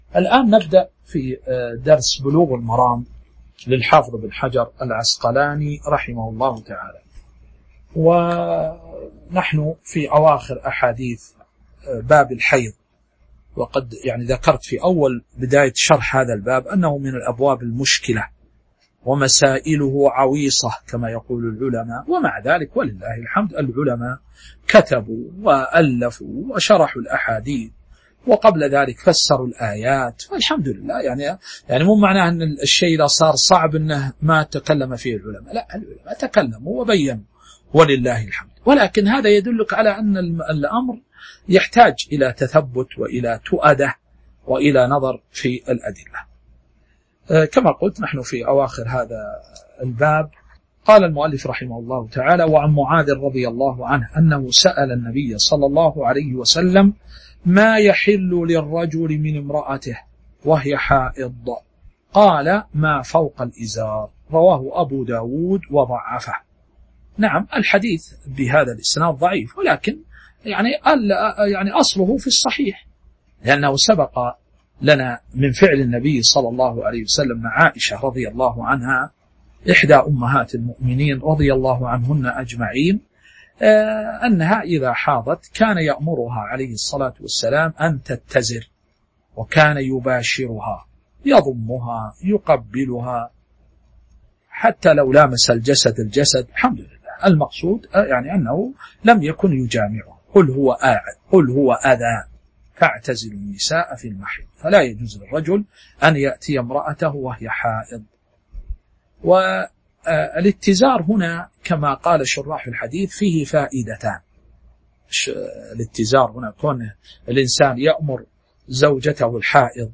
تاريخ النشر ١٣ محرم ١٤٤٥ هـ المكان: المسجد النبوي الشيخ